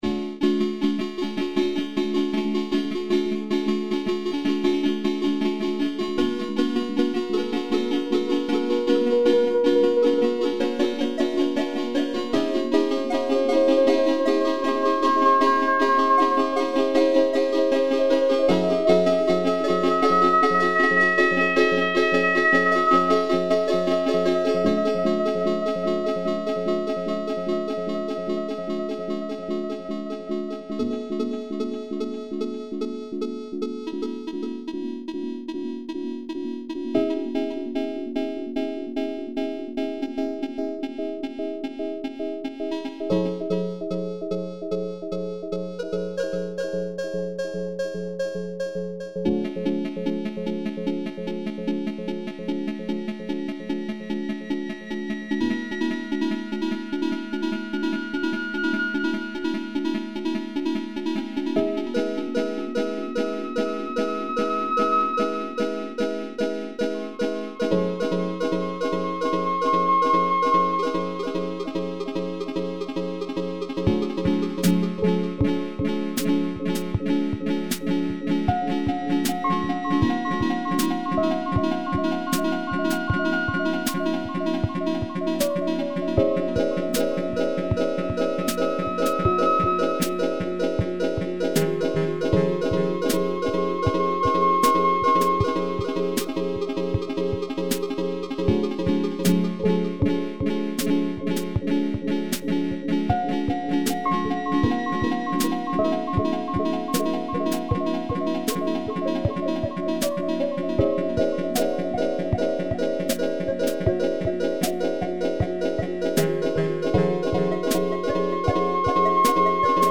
a floating ambientish tune